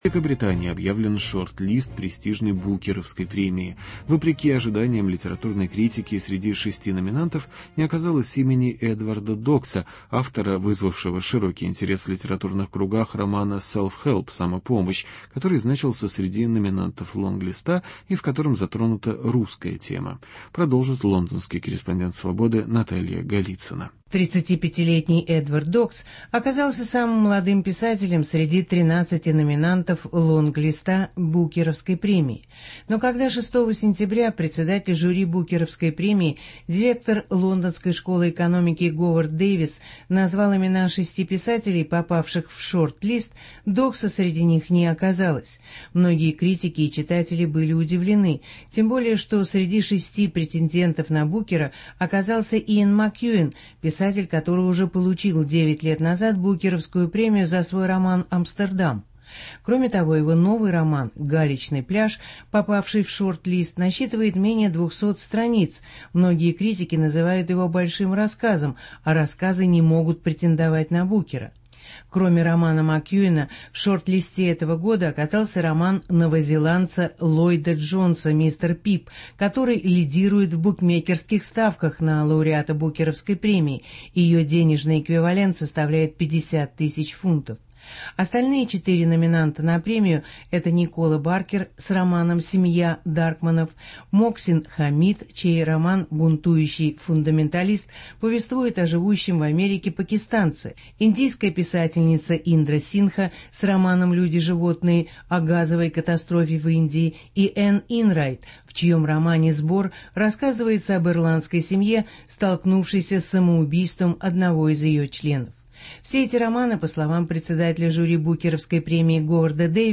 Интервью с британским писателем Эдвардом Доксом.